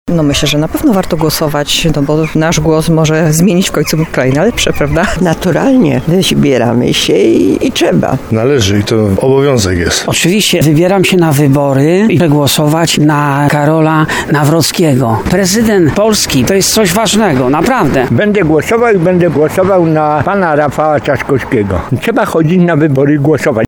Tarnowianie, z którymi rozmawialiśmy zgodnie przyznają, że zamierzają pójść do urn.
13wybory-sonda.mp3